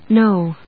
/nóʊ(米国英語), nˈəʊ(英国英語)/